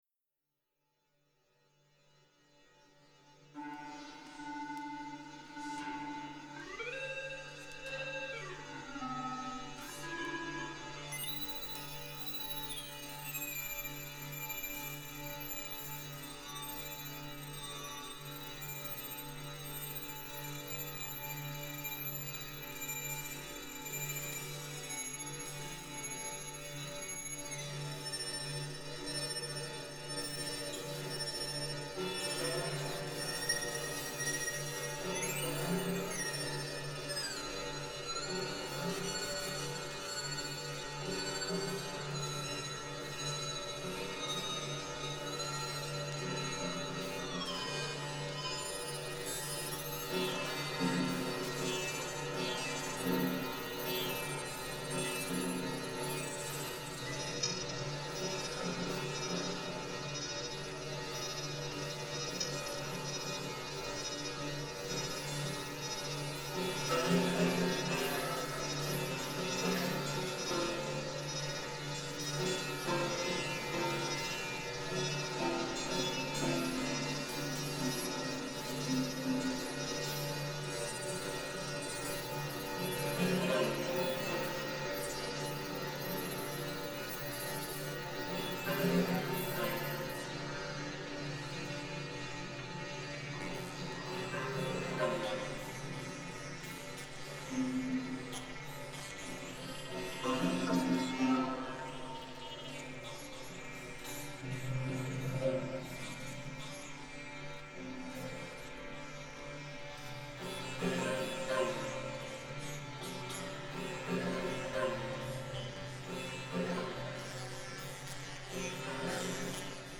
Sibilant and entangled, quant(itatively) disabused.
SitarCityPaths.mp3